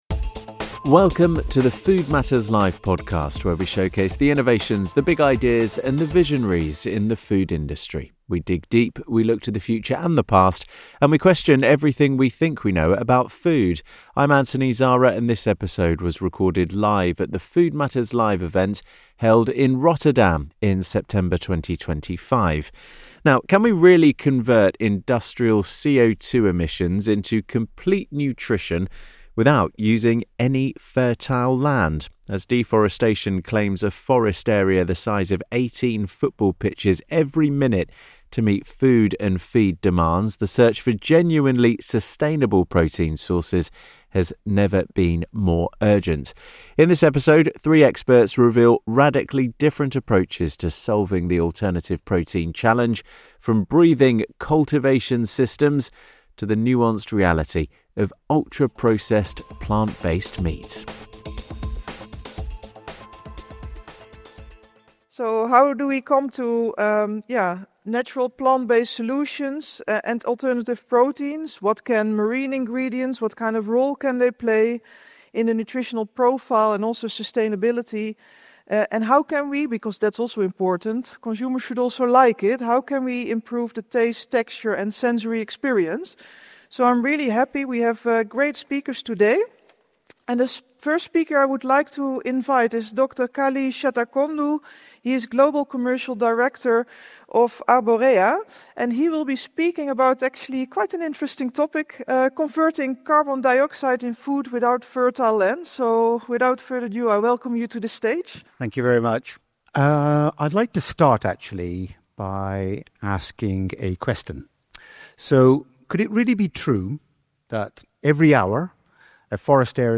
In this episode of the Food Matters Live podcast, recorded at our event in Rotterdam in September 2025, three experts reveal radically different approaches to solving the alternative protein challenge - from breathing cultivation systems to the nuanced reality of ultra-processed plant-based meat. We discover how technological advances are putting microalgae at the centre of the alternative protein conversation, we delve into the debate around ultra-processed foods, and the hidden challenge: even the most sustainable ingredient innovation can fail if supply chain realities aren't considered from day one.